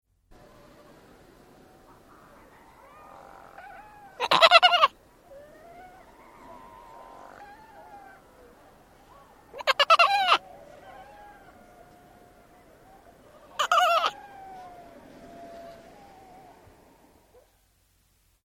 На этой странице собраны звуки морских котиков — забавные и живые голоса этих удивительных животных.
Мяукающий звук морского котика